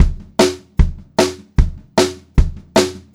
152SPTOM1 -L.wav